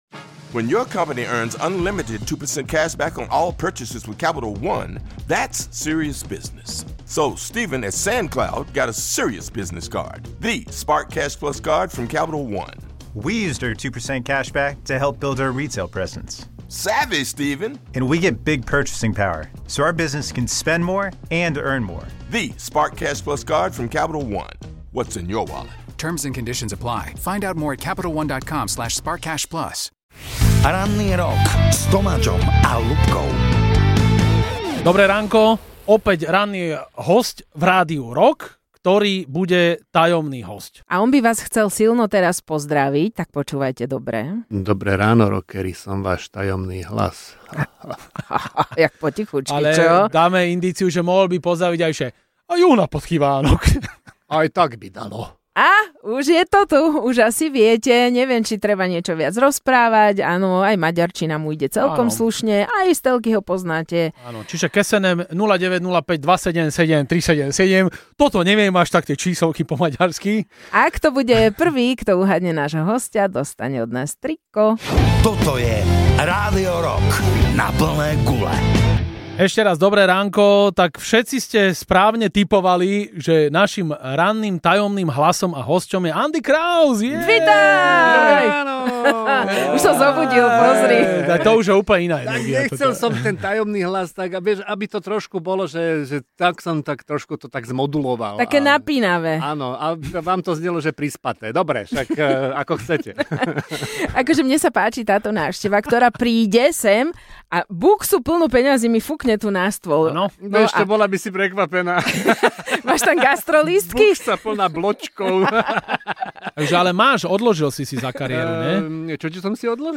Do štúdia Rádia Rock zavítal Andy Kraus.